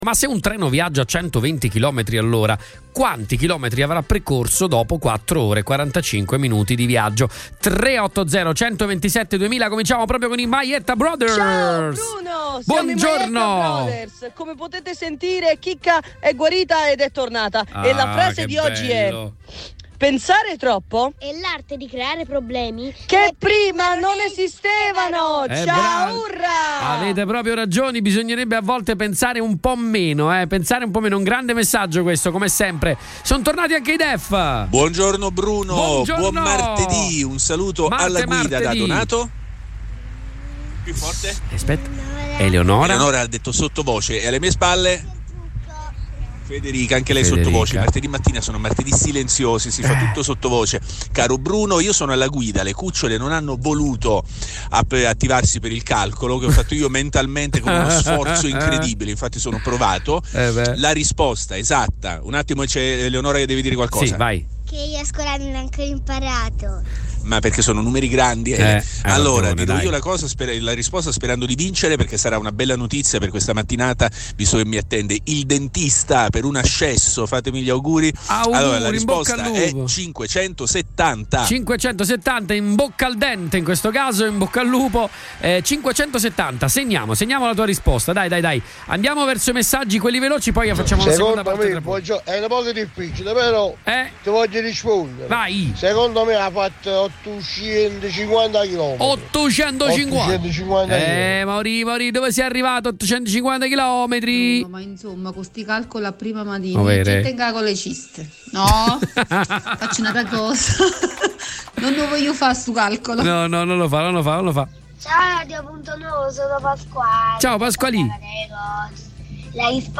LE RISPOSTE DEGLI ASCOLTATORI